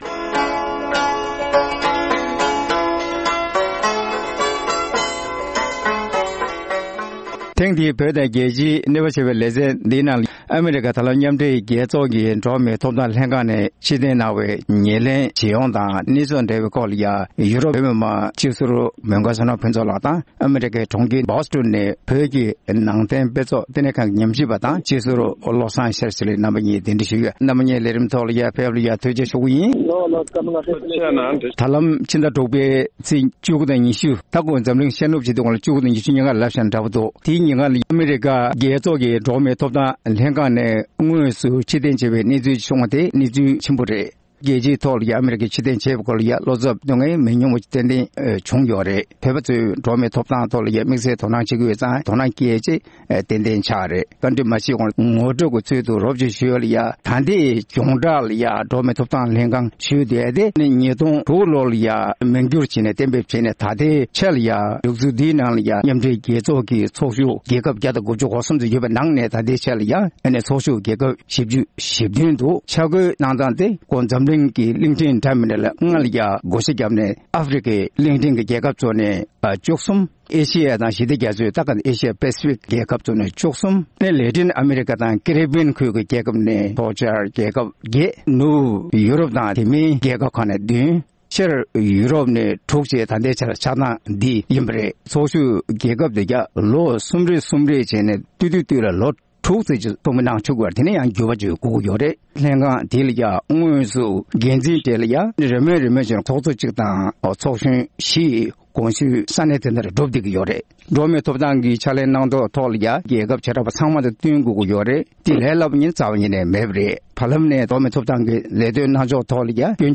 ཨ་མི་རི་ཀ་མཉམ་འབྲེལ་རྒྱལ་ཚོཌ་ཀྱི་འགྲོ་བ་མིའི་ཐོབ་ཐང་ལྷན་ཁང་ནས་ཕྱིར་འཐེན་གནང་བའི་ཤུགས་རྐྱེན་ཇི་ཡོང་དང་འབྲེལ་བའི་ཐད་གླེང་མོལ།